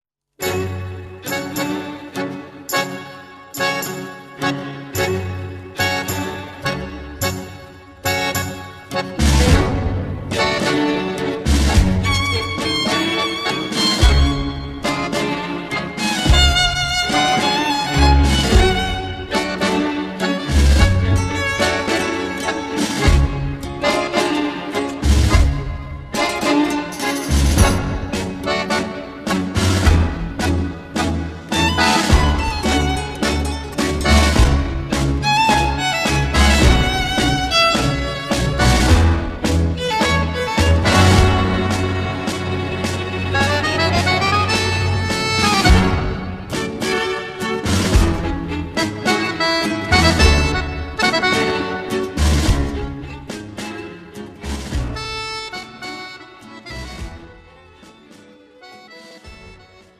음정 (-1키)
장르 뮤지컬 구분